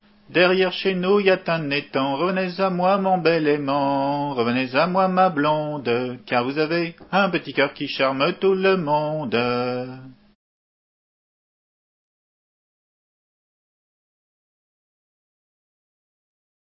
Entendu au fest-deiz de la mission bretonne le 10 mars 1990